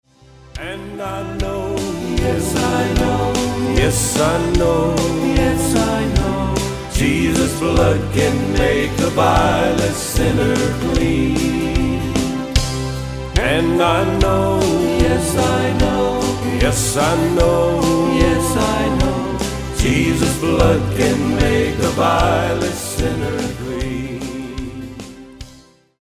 Autoharp
Drums, Lead and Harmony Vocals
Guitar
Bass
Keyboards
Rhythm guitar